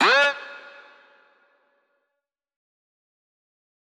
SG - Vox 6.wav